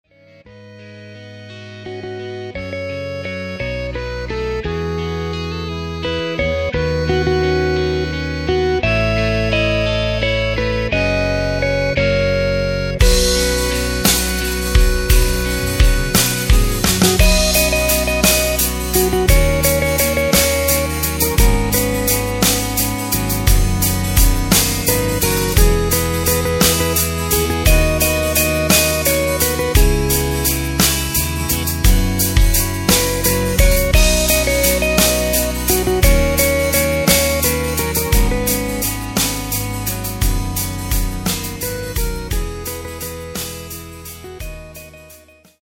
Takt:          6/8
Tempo:         86.00
Tonart:            B